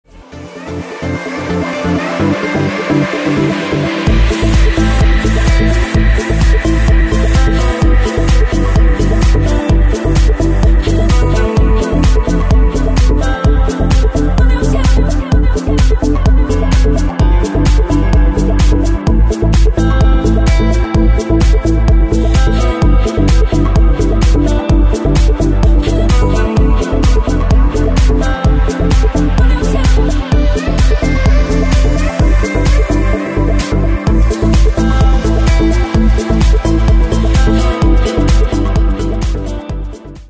• Качество: 128, Stereo
женский голос
dance
Electronic
progressive house
Trance